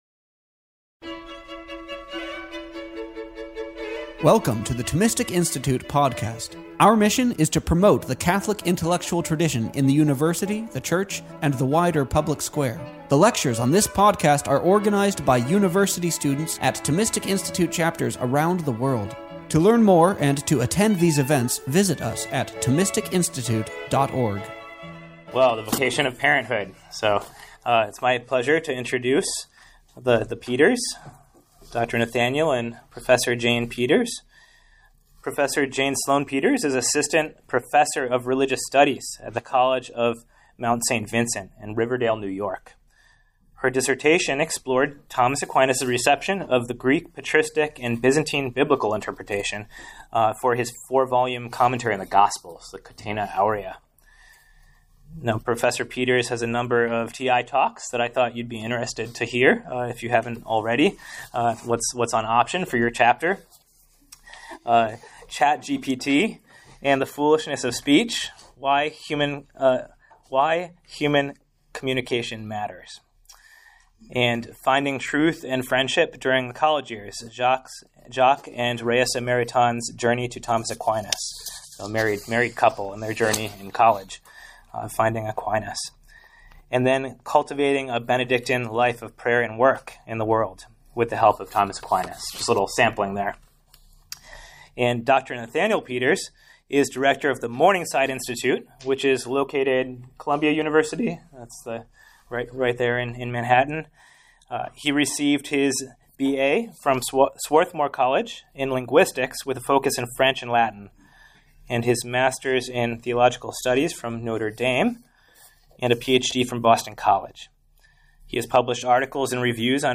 This lecture was given on January 24th, 2025, at Dominican House of Studi…